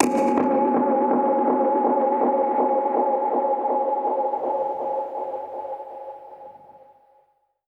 Index of /musicradar/dub-percussion-samples/125bpm
DPFX_PercHit_A_125-01.wav